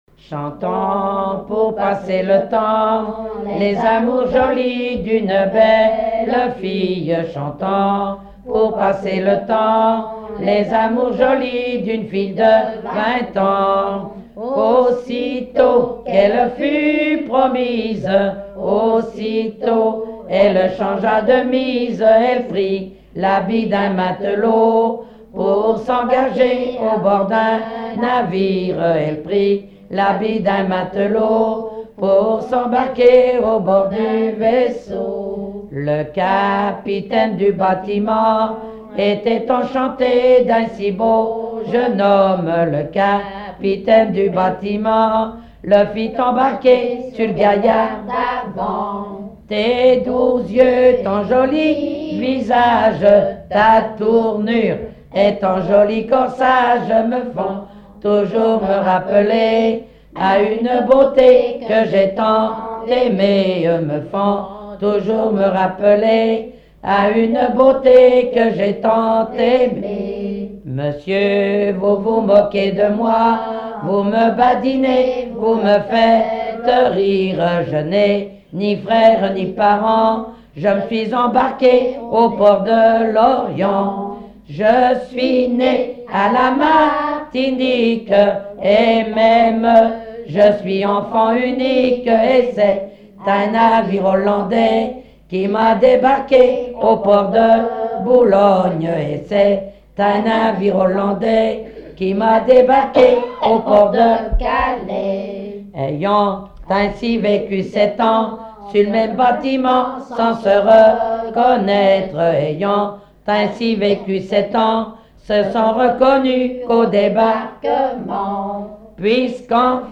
Genre strophique
collecte en Vendée
Pièce musicale inédite